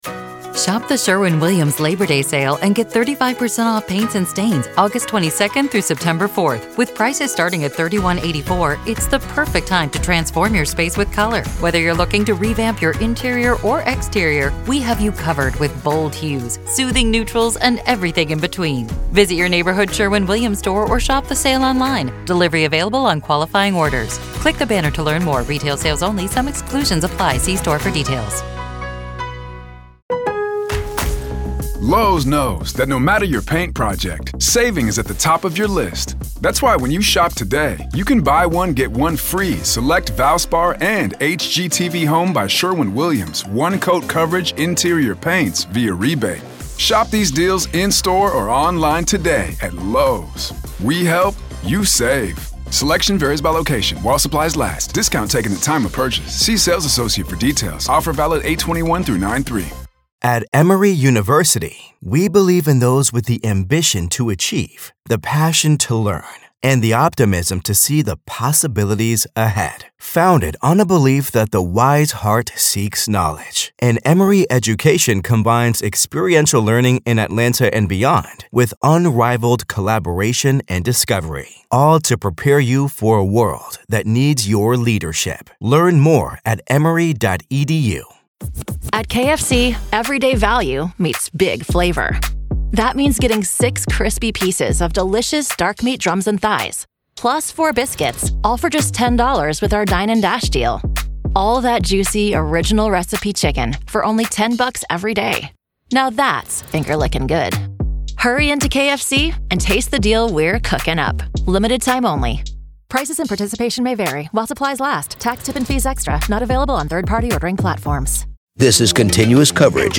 Join us this weekend on our riveting podcast as we journey through the most captivating interviews and enthralling audio snippets that delve into the enigmatic case against Lori Vallow Daybell.